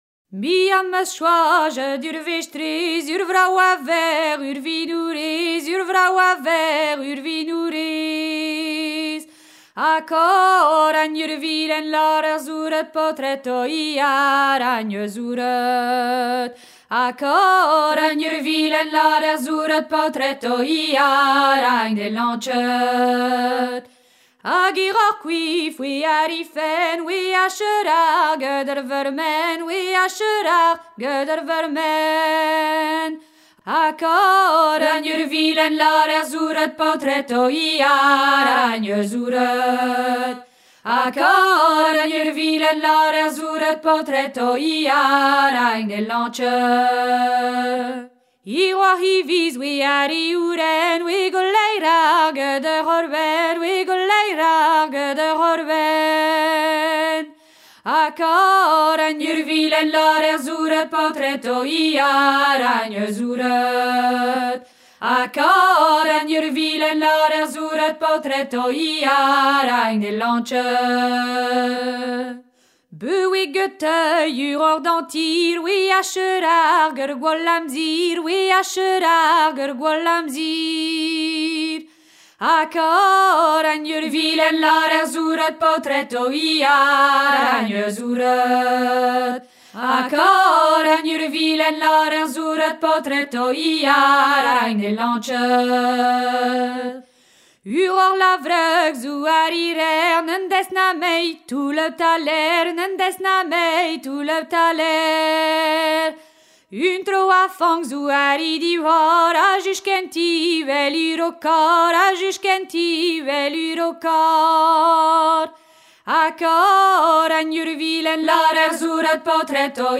Son e gwenedeg